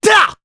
Mitra-Vox_Attack2_jp.wav